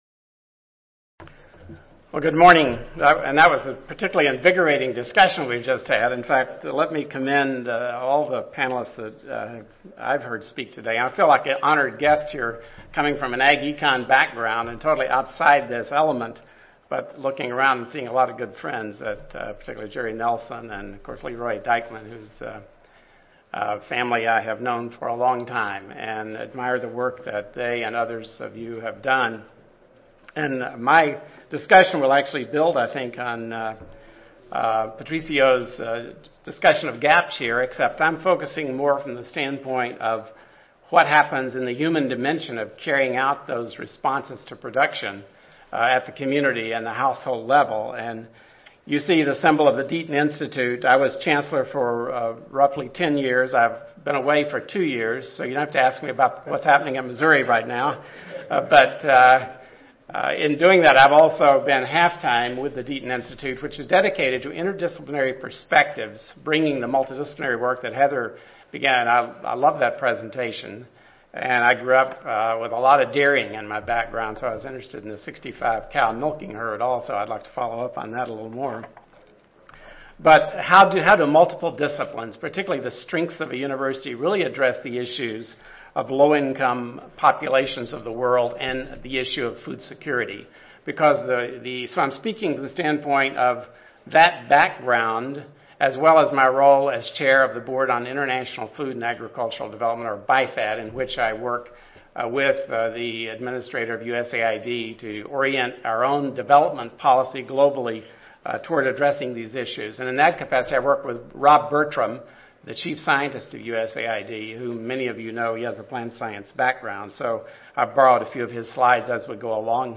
University of Missouri Audio File Recorded Presentation